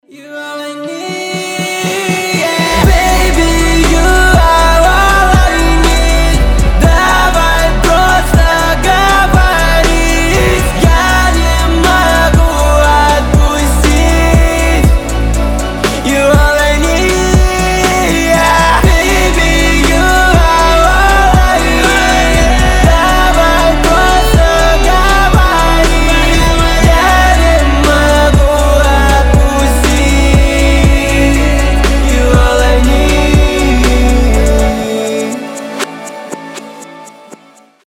• Качество: 320, Stereo
громкие